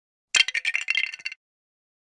meme sound board Lego Breaking Sound sound effects free download